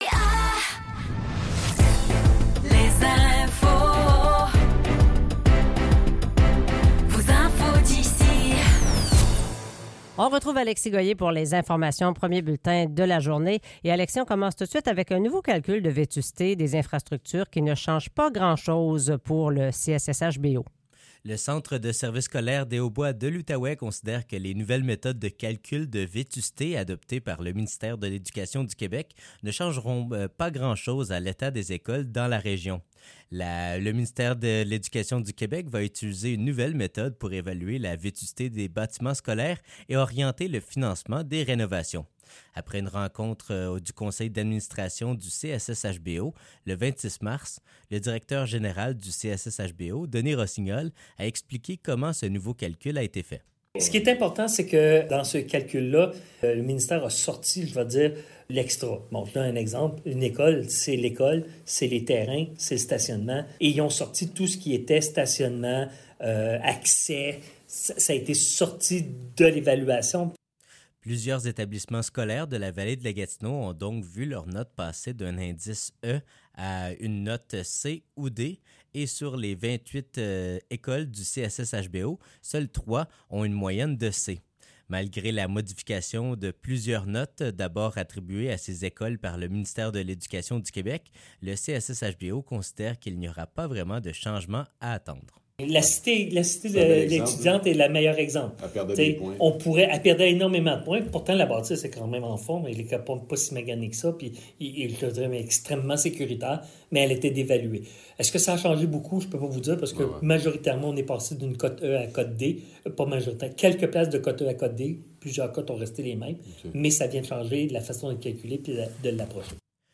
Nouvelles locales - 9 avril 2024 - 7 h